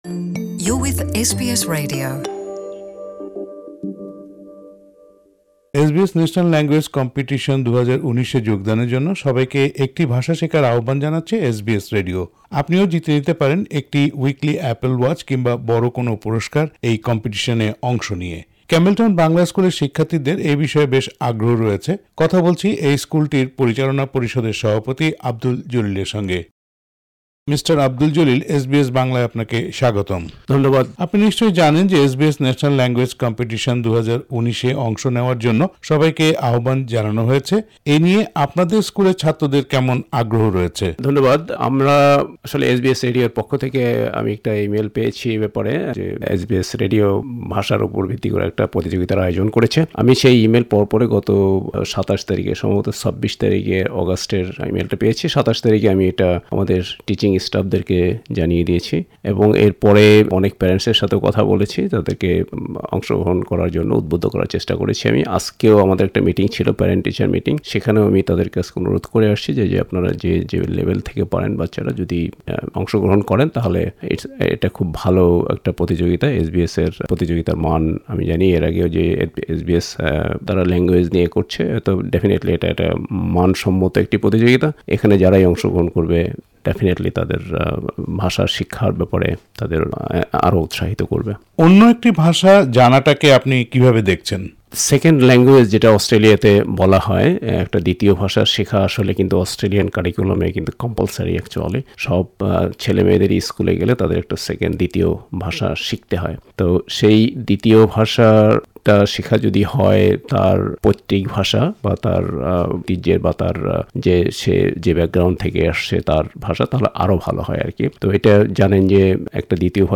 সাক্ষাৎকারটি বাংলায় শুনতে উপরের অডিও প্লেয়ারটিতে ক্লিক করুন।